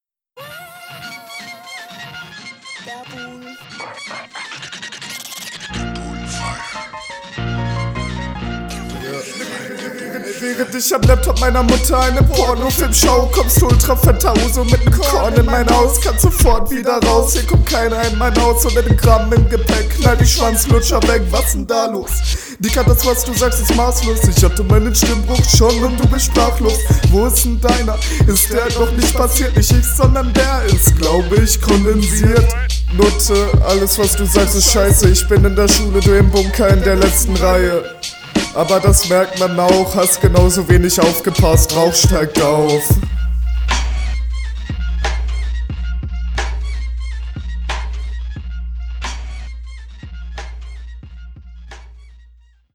interessante stimme hast du auf jeden fall! das mit dem ultrafettenhuso hat mich halt voll …